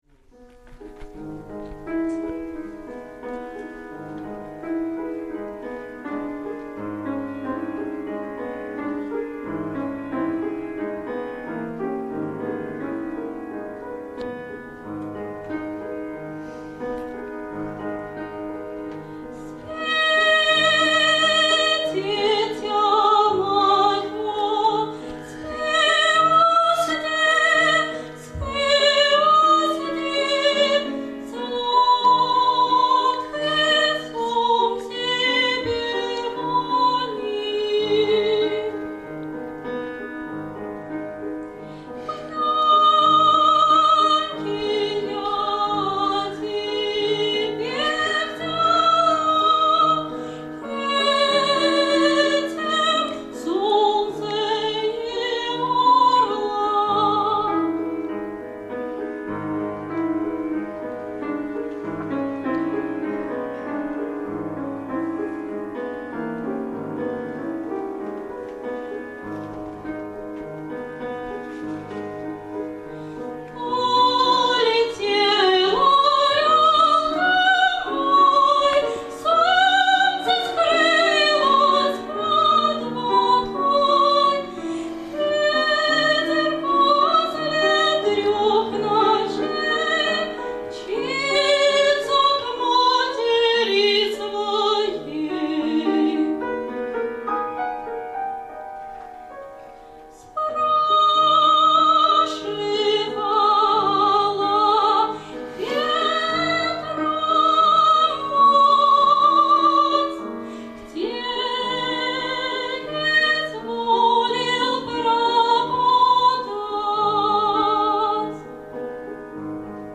Живой звучание . Концерт